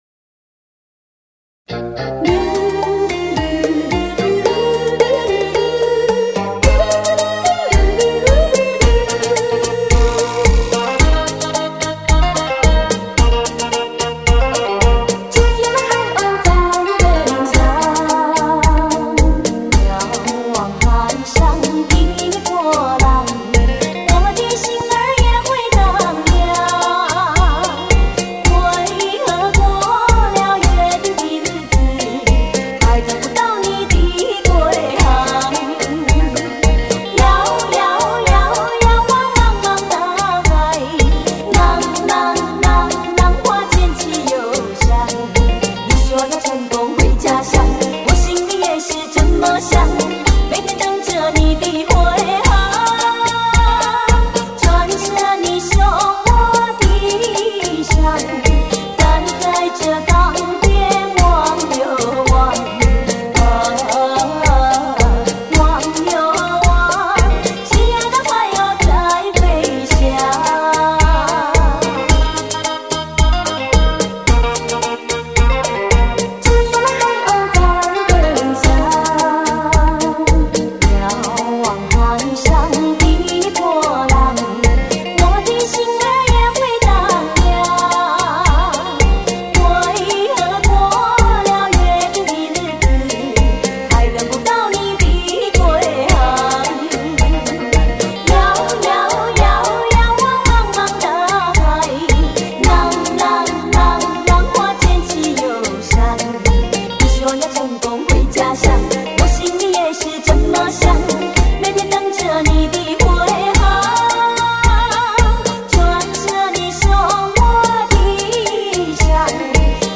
每张专辑都是从新配器，适合广场舞。
广场舞专用